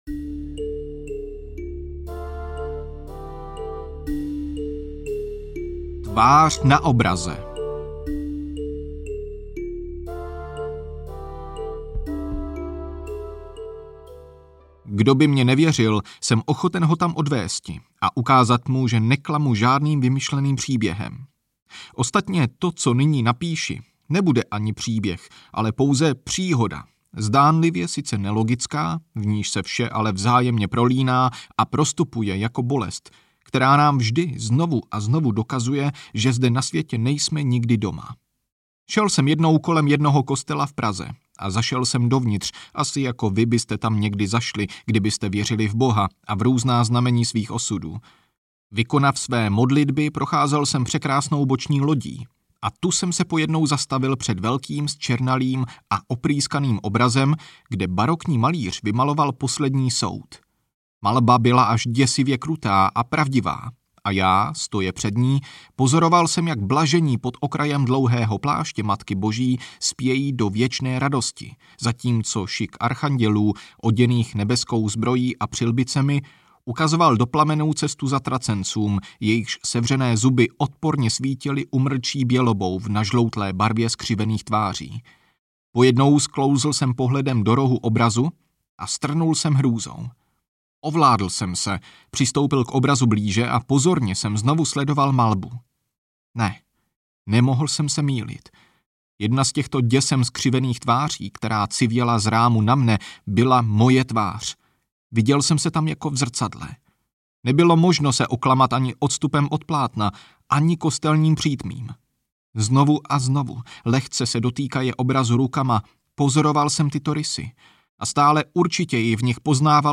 Peníz z noclehárny audiokniha
Ukázka z knihy